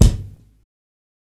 TC3Kick13.wav